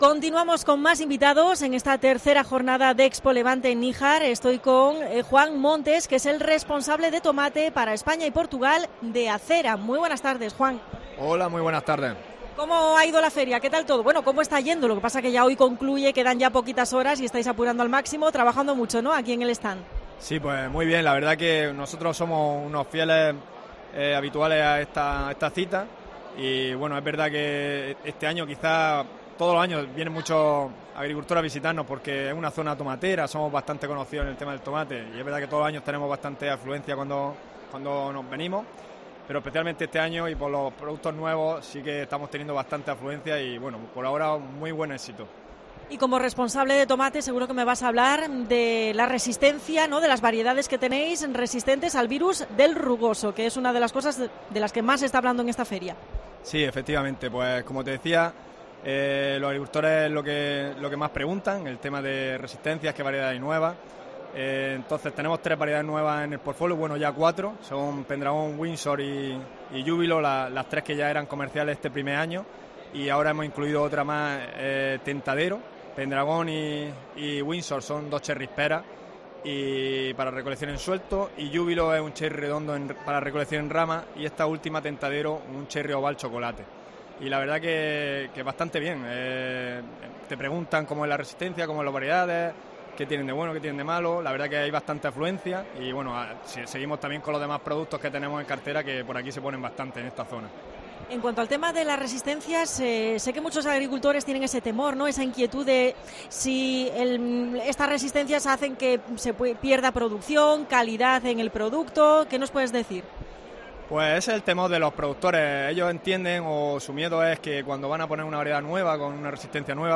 Especial COPE Almería desde ExpoLevante en Níjar.